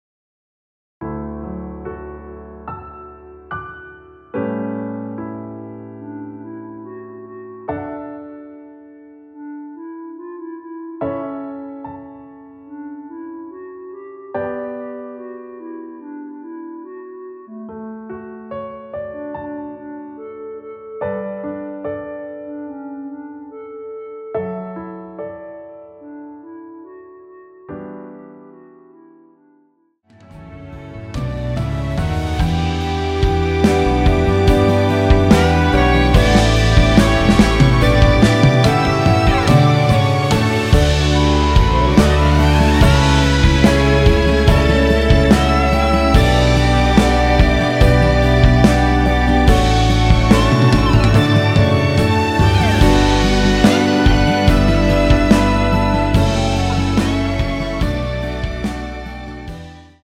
노래가 바로 시작 하는 곡이라 전주 만들어 놓았습니다.
6초쯤에 노래 시작 됩니다.(미리듣기 참조)
D
앞부분30초, 뒷부분30초씩 편집해서 올려 드리고 있습니다.
중간에 음이 끈어지고 다시 나오는 이유는